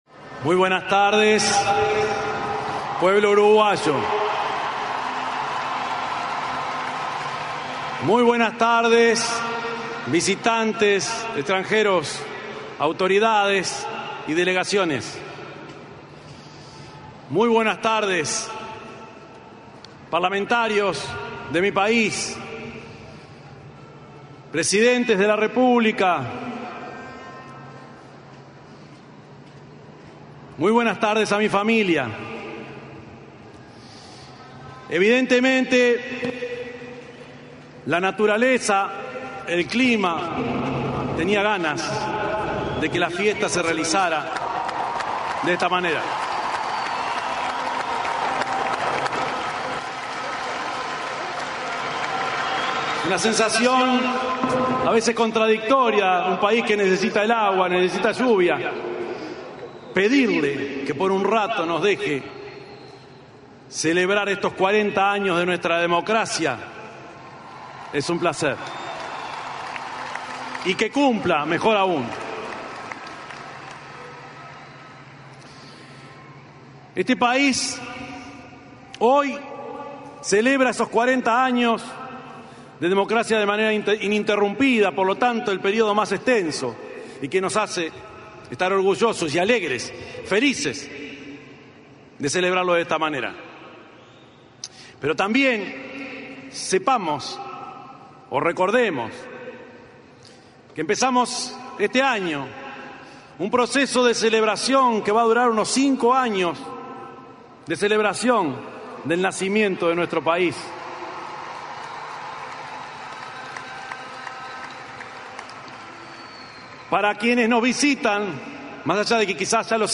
Palabras del presidente Yamandú Orsi
Este sábado 1.° de marzo, en la plaza Independencia, en oportunidad del traspaso de mando presidencial, se expresó el mandatario de la República,